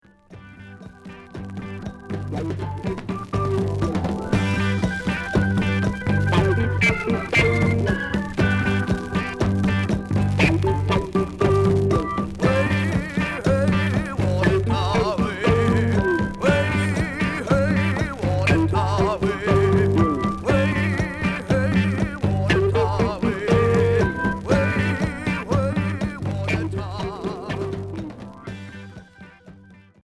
Progressif